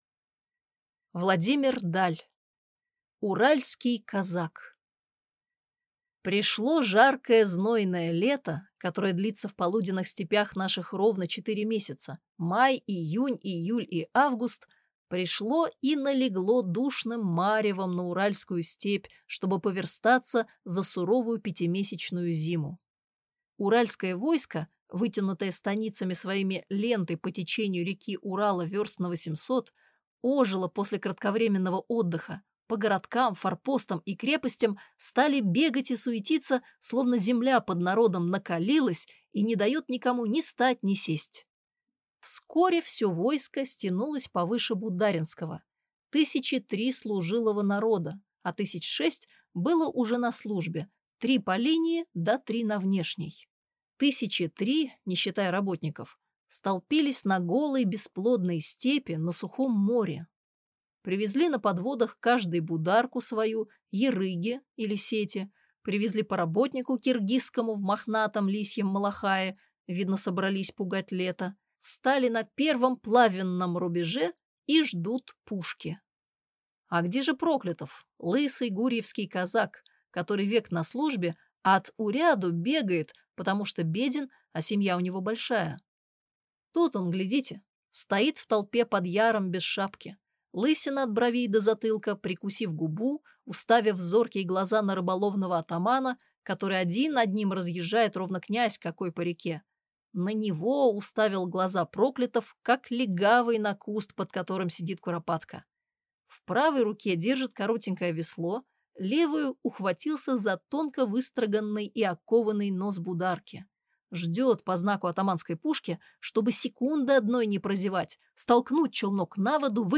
Аудиокнига Уральский казак | Библиотека аудиокниг